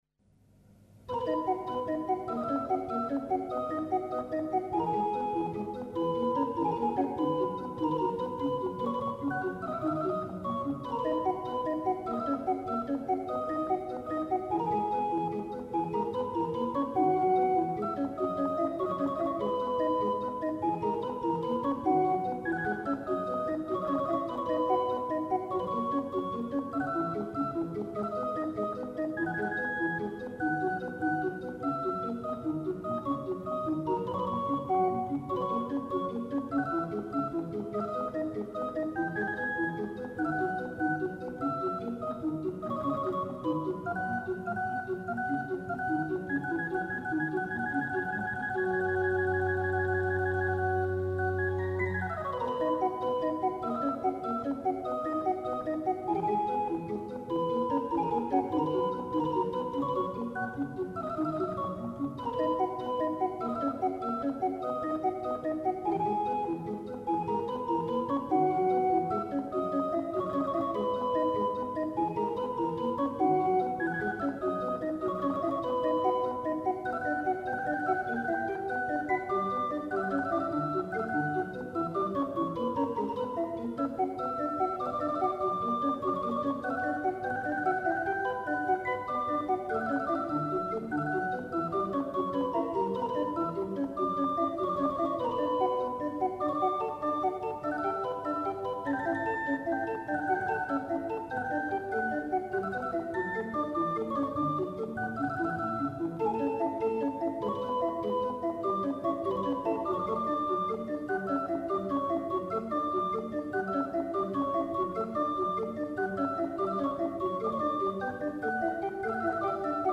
Notation sur ordinateur de la notation pour orgue à cylindre
Enregistré sur mon positif